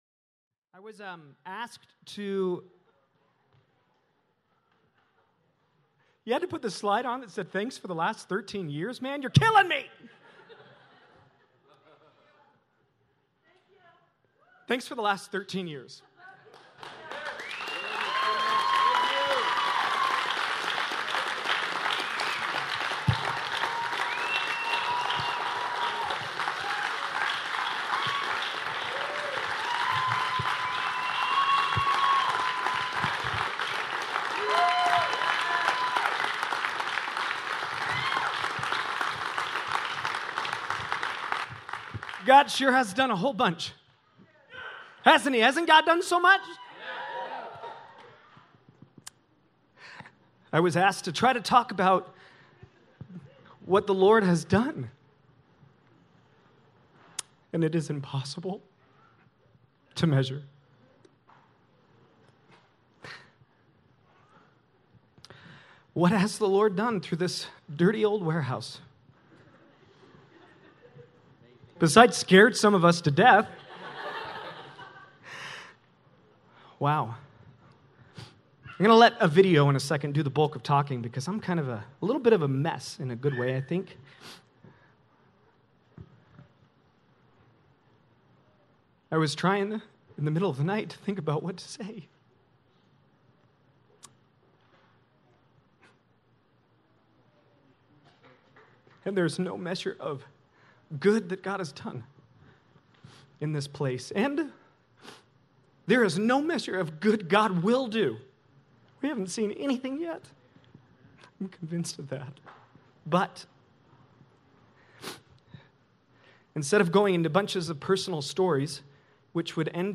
A message from the series "The Core."